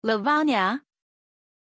Aussprache von Lavanya
pronunciation-lavanya-zh.mp3